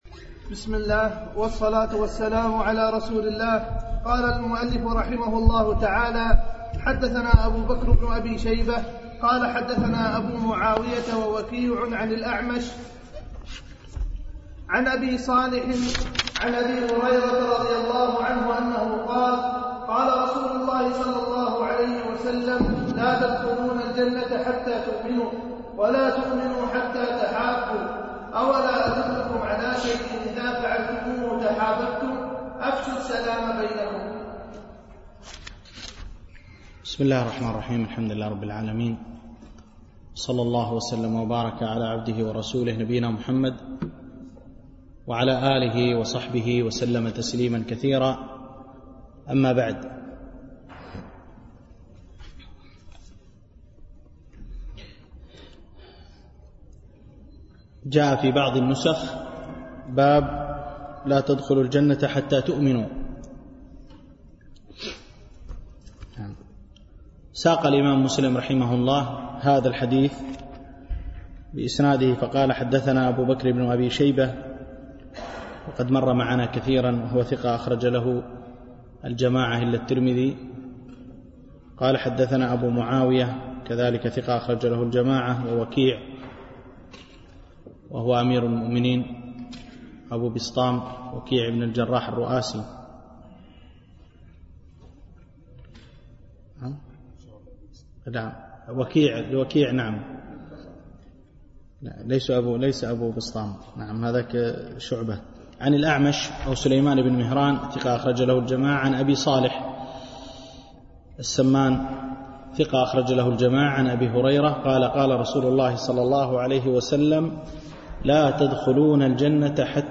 الألبوم: دروس مسجد عائشة (برعاية مركز رياض الصالحين ـ بدبي)